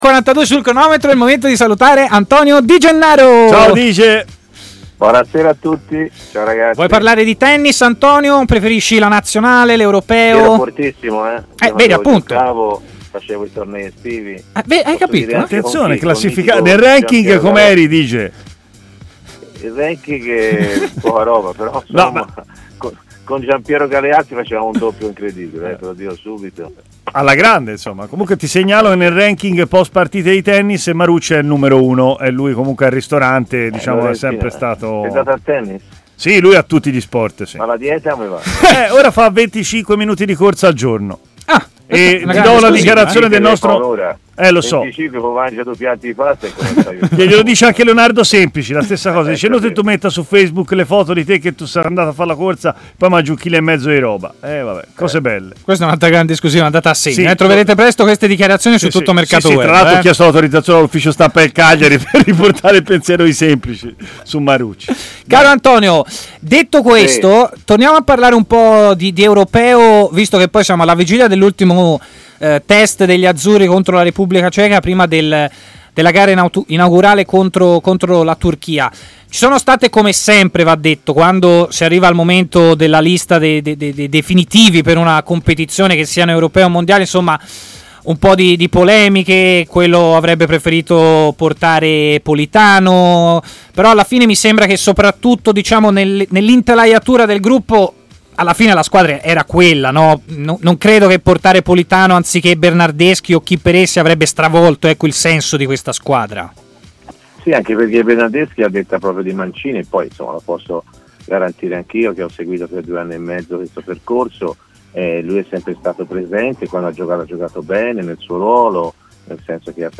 ha parlato in diretta a Stadio Aperto